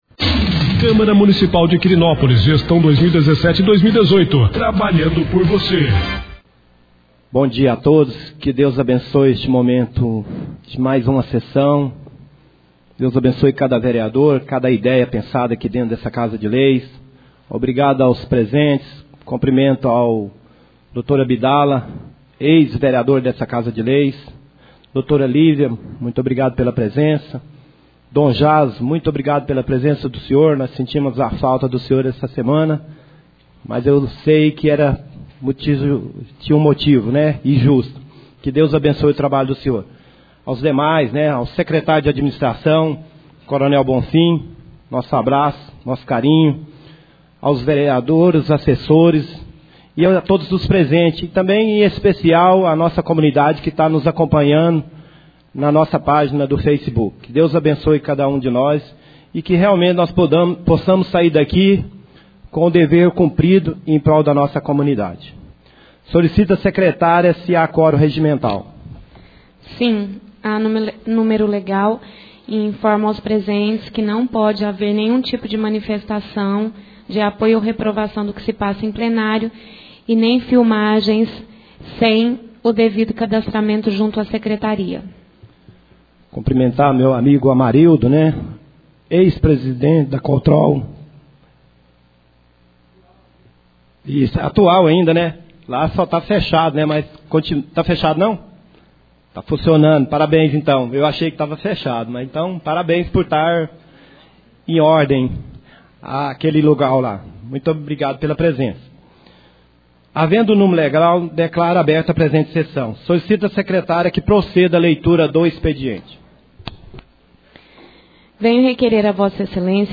3ª Sessão Ordinária do Mês de Dezembro 2017